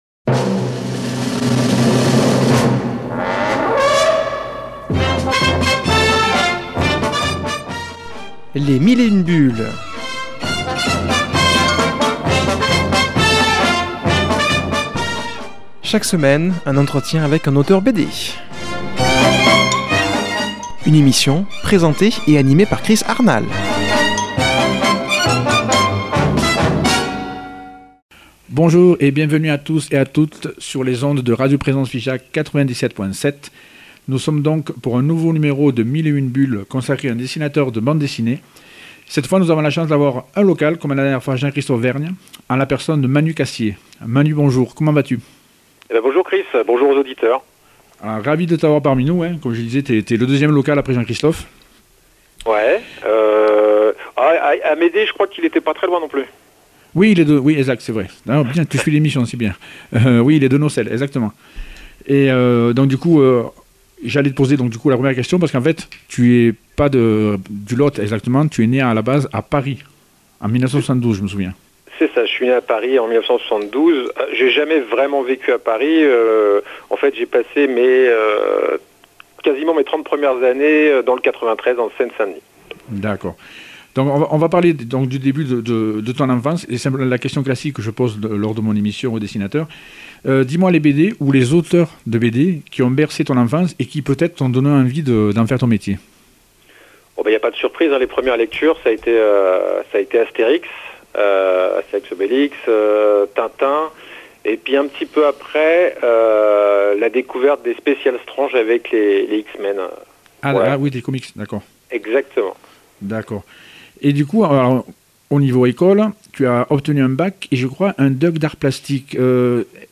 reçoit par téléphone